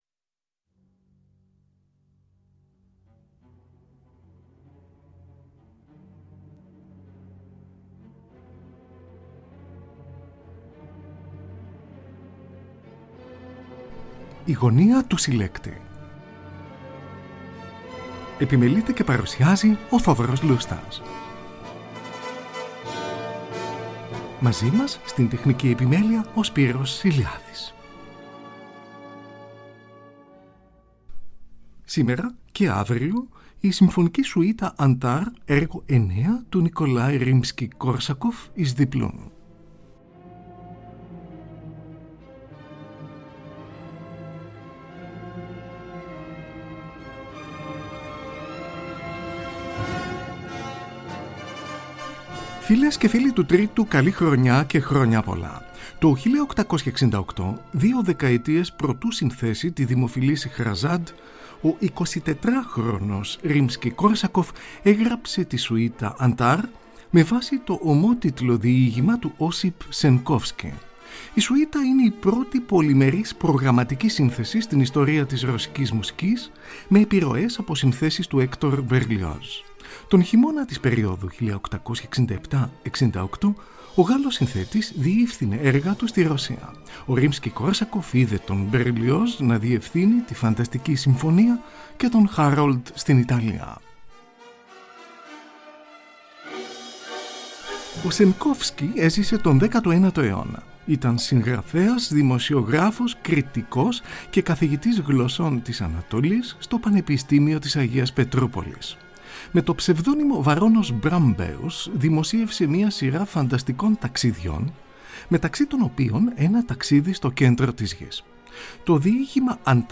Στην εκδοχή για πιάνο με τέσσερα χέρια